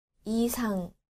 • 이상
• isang